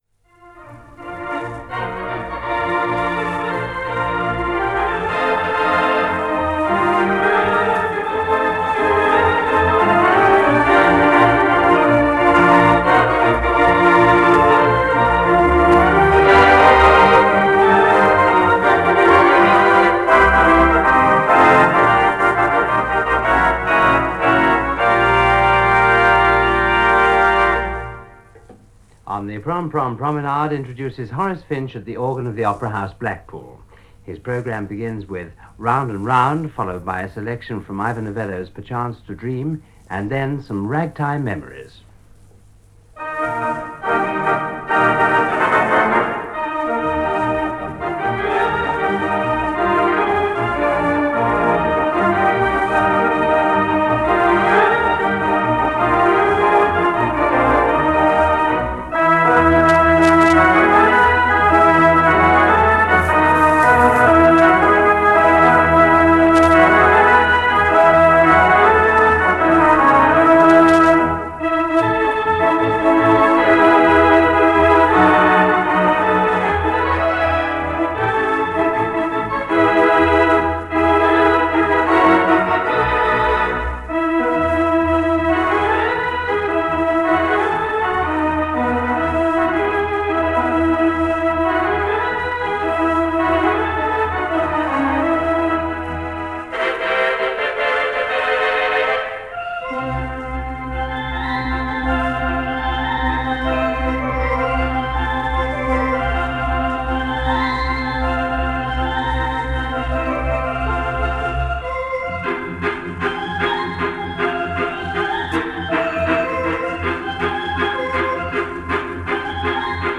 Opera House, Blackpool 3/13 Wurlitzer
Surface noise from acetate